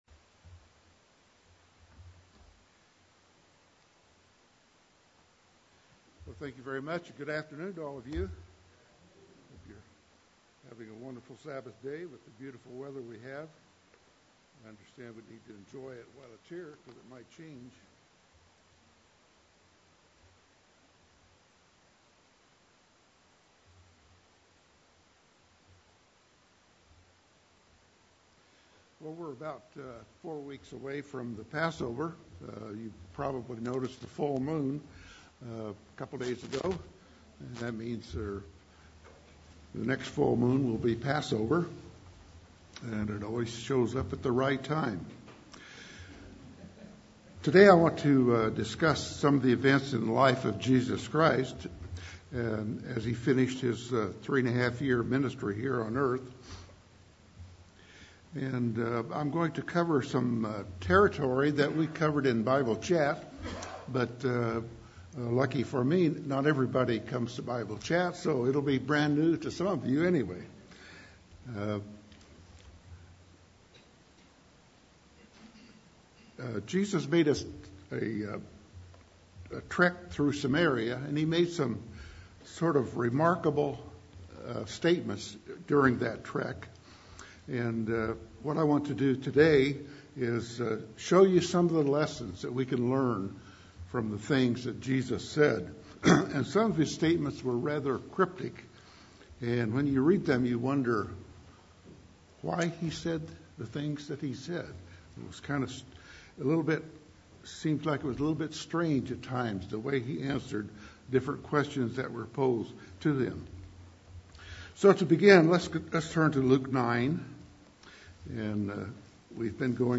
UCG Sermon